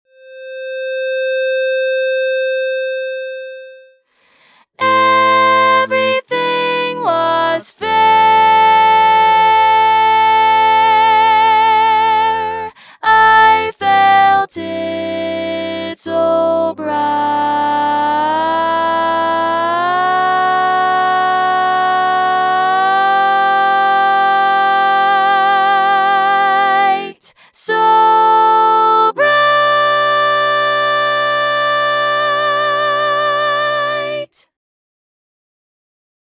Key written in: C Major
Type: SATB